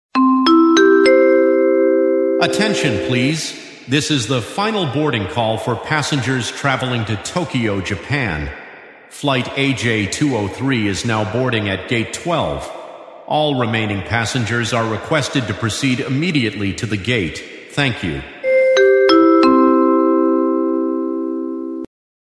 Announcement Airport Bouton sonore